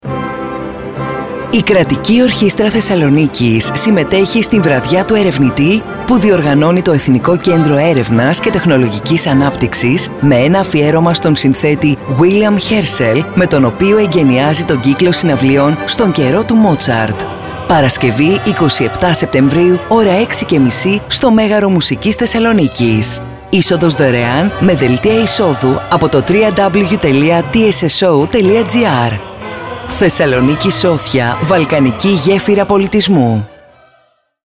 Ραδιοφωνικό σποτ 27σεπ24_Αφιέρωμα στον William Hershel Κατηγορία: Ραδιοφωνικά σποτ Ηχος Ραδιοφωνικό σποτ 27σεπ24_Αφιέρωμα στον William Hershel.mp3 Σχετικό με τις εξής εκδηλώσεις: Αφιέρωμα στον William Herschel - Βραδιά του Ερευνητή